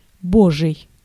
Ääntäminen
IPA: /di.vɛ̃/